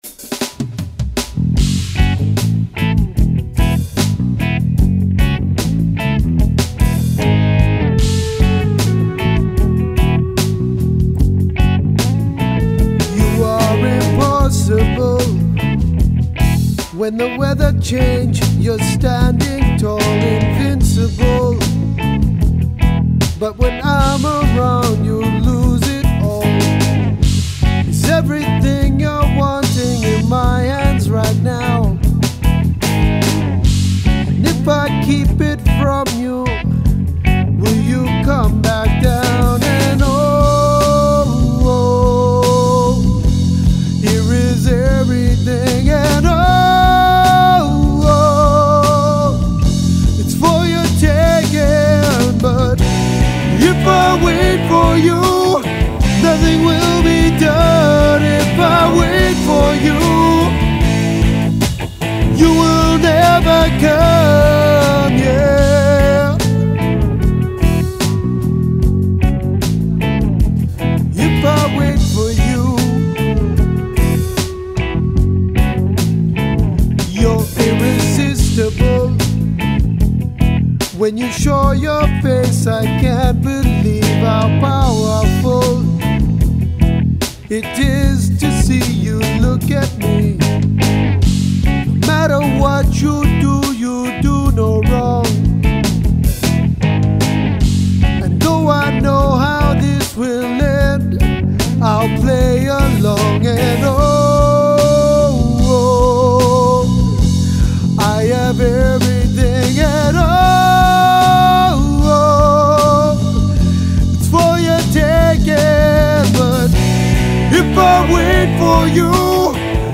drummer
guitarist
This yet-to-be-named groove trio
the bass guitar.
From these productive jams have come some rough pre-production demos - one of which they would like to share online.